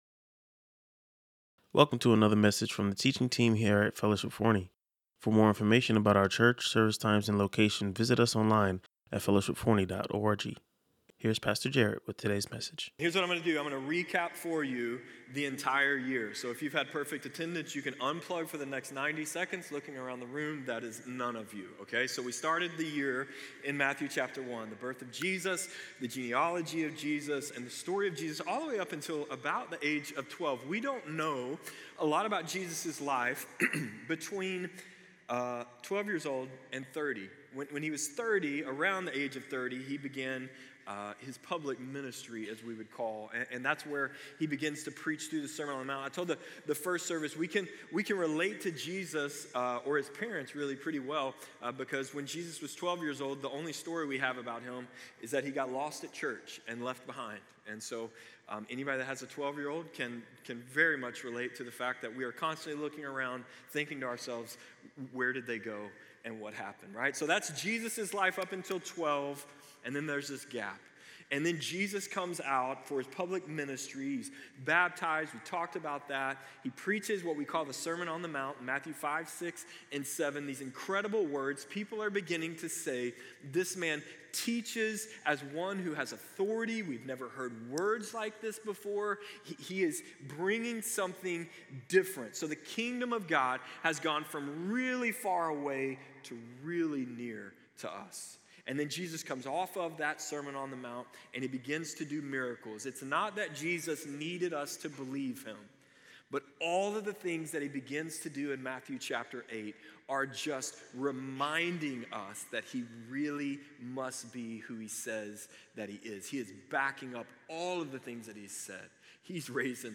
He challenged the congregation to identify one courageous act they could take this week for God’s glory, reminding them that while faith doesn’t guarantee an easier life,